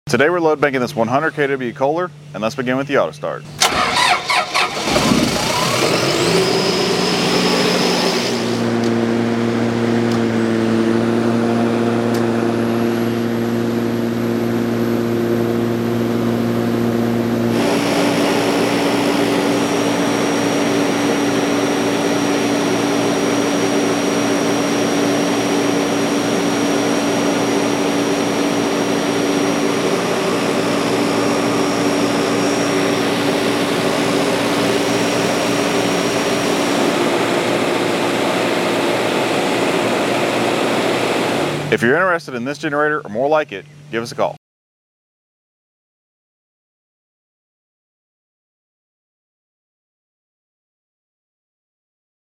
102kW Kohler/Rehlko Diesel Generator For sound effects free download